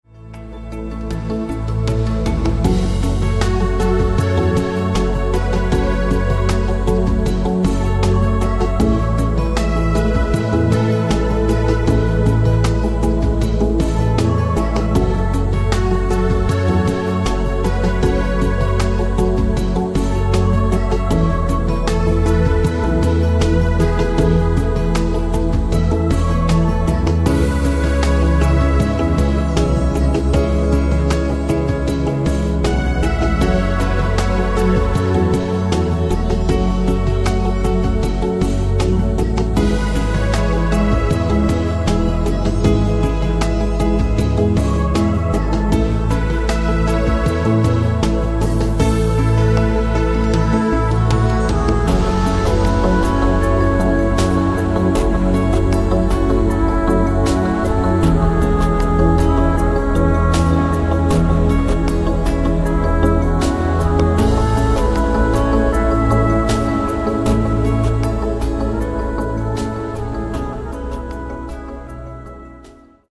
MP3 - Wellness & Chillout